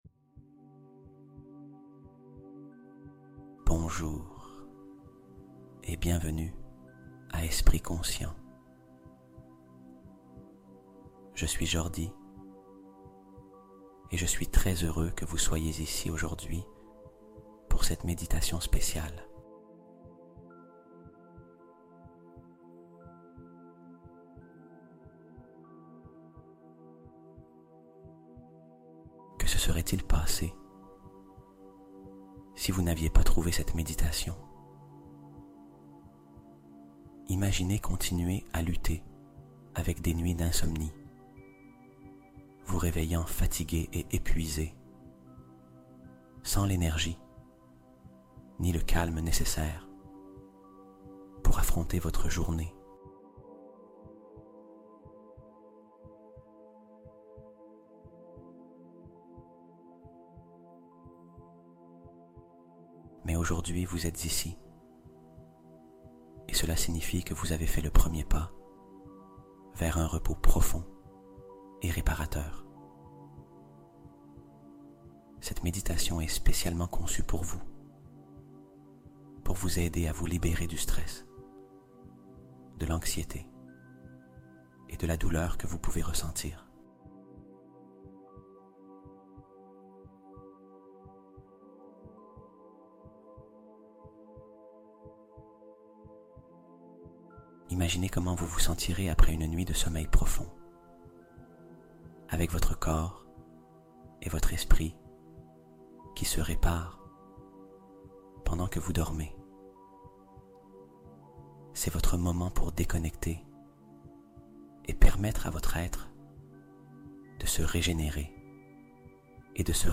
Vibration 777 Hz : Éveiller ses capacités de perception et d'équilibre